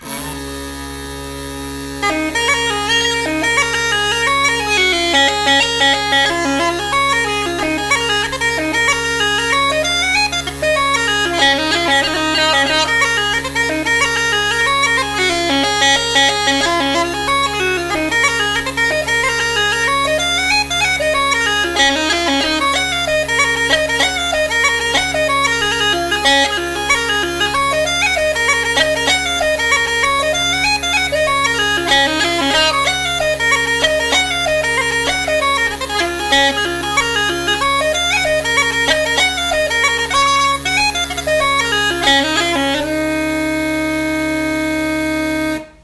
Pipes – Cooley’s